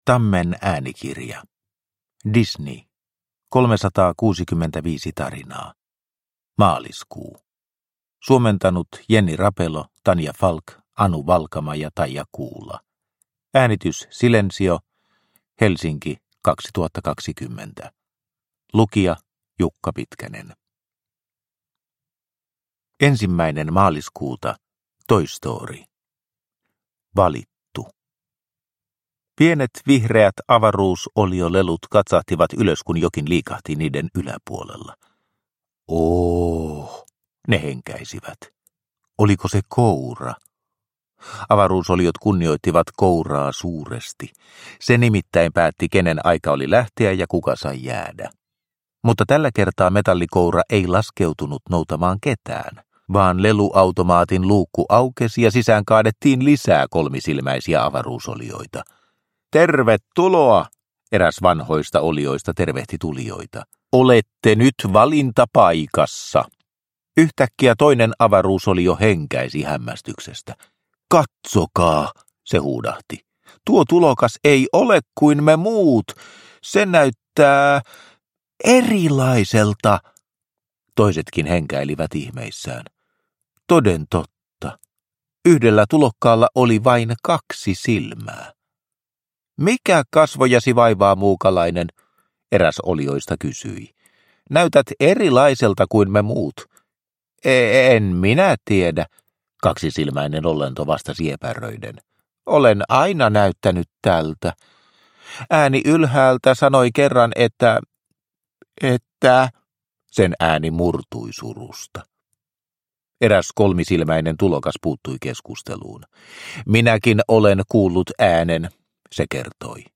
Disney 365 tarinaa, Maaliskuu – Ljudbok – Laddas ner